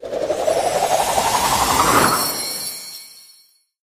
char_roll_out_02.ogg